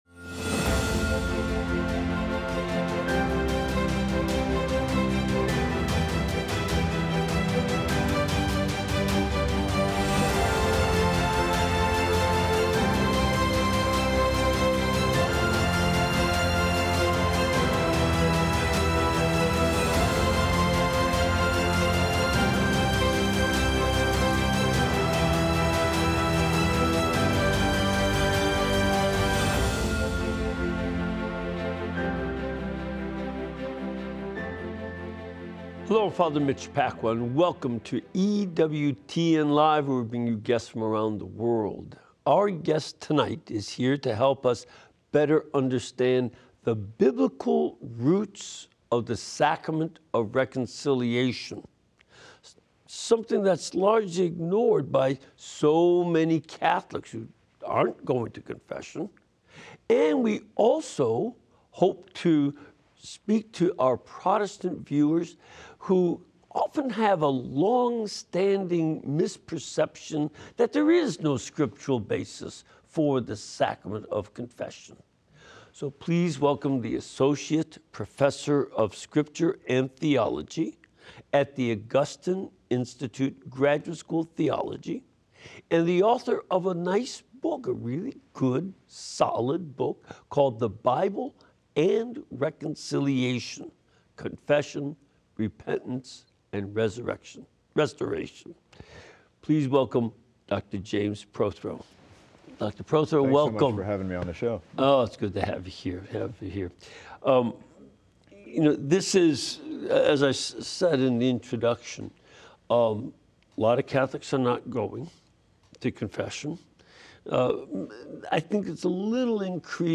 interviews various guests seeking to teach and prepare us for evangelism. Learn more about your faith and reawaken your desire to bring others to the Church.